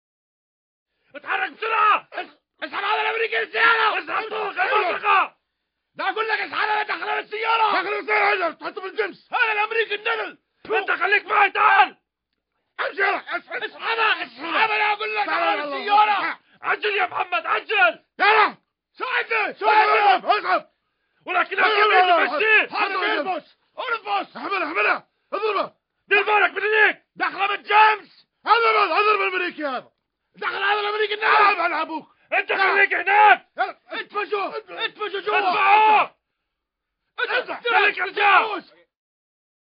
Разъяренные голоса двух арабов в перепалке